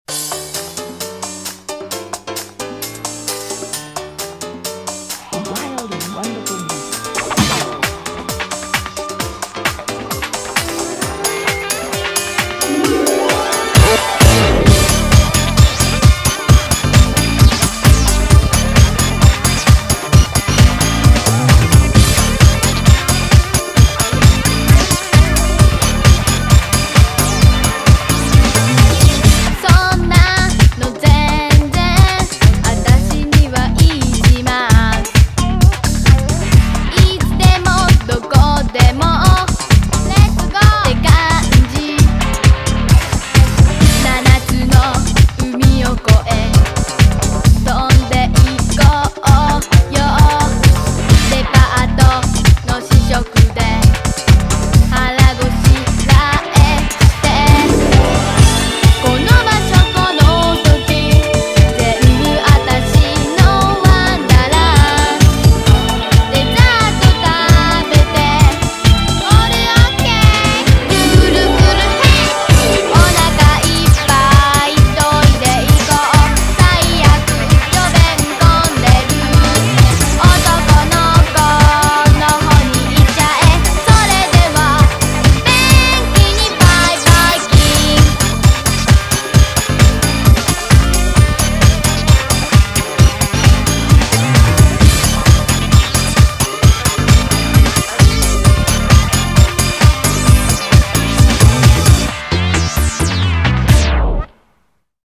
BPM132
Audio QualityPerfect (High Quality)
A cute, smiley, EZ pop song to grin and sing along to!